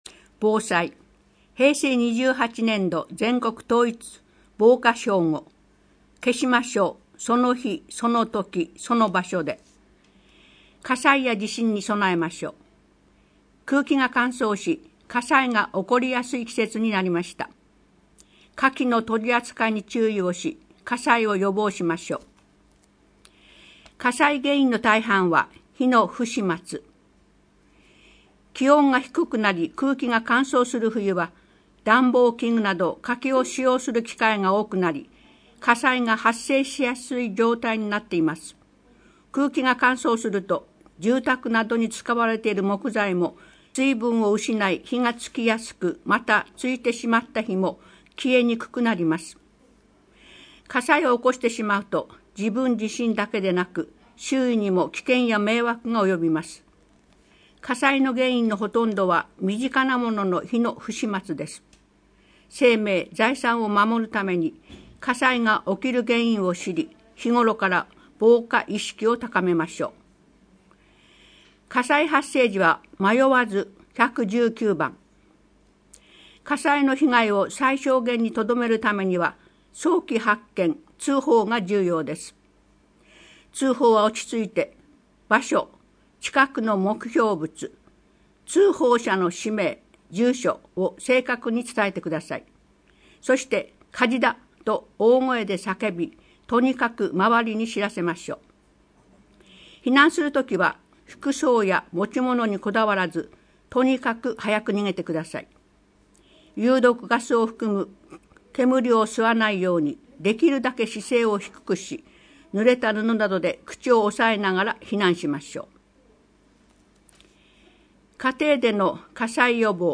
声の広報は、朗読ボランティア「野ばらの会」様のご協力により、目の不自由な人や高齢者など、広報紙を読むことが困難な人のために「声の広報筑西People」としてお届けしています。